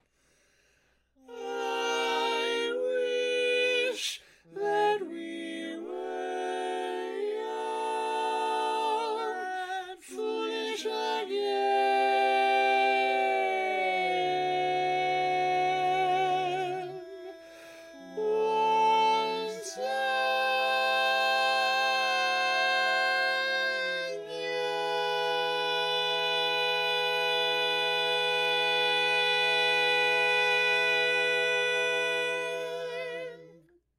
Key written in: E Major
Type: Barbershop